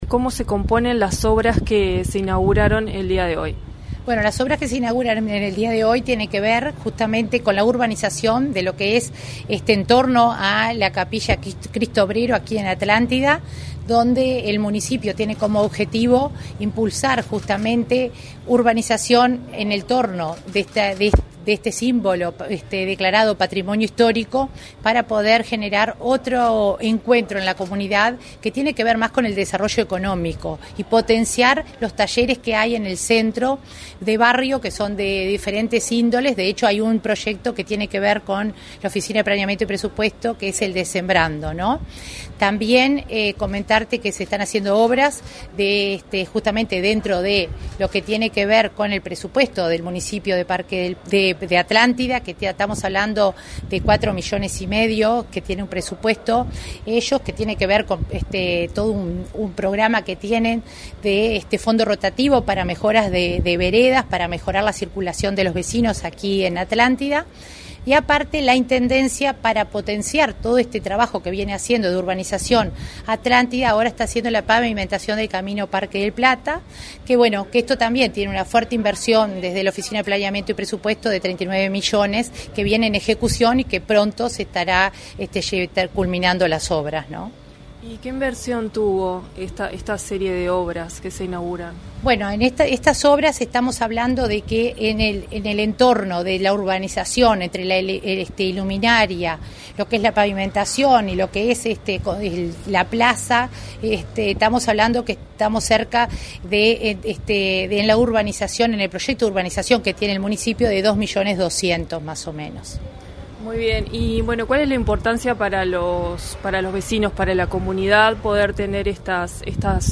Entrevista a la coordinadora de Descentralización de OPP, María de Lima
Tras participar en el acto de inauguración de obras de remodelación en la plaza Idilio Conde, en Atlántida, este 6 de diciembre, María de Lima realizó